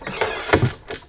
woodenChest_open.WAV